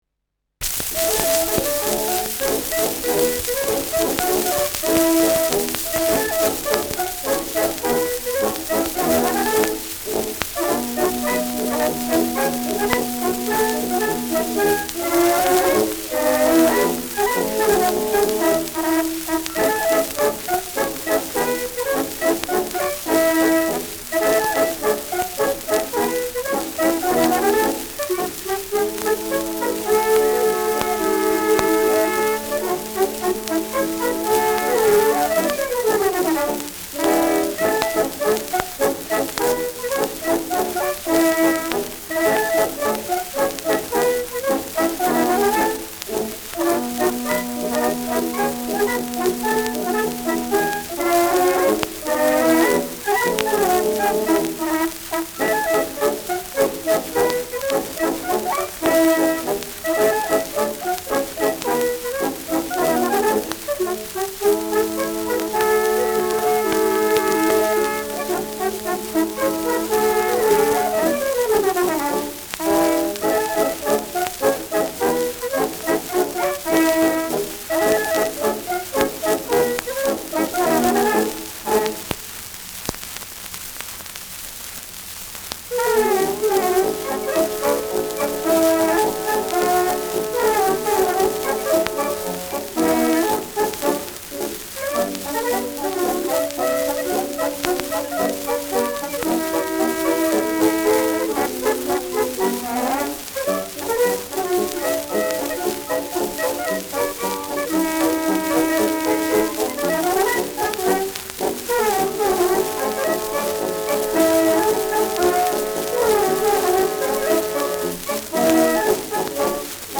Schellackplatte
Tonnadel „rutscht“ zu Beginn über einige Rillen : präsentes Rauschen : abgespielt : leiert : gelegentliches Knacken : leichtes Nadelgeräusch
Kapelle Waxnstoana, München (Interpretation)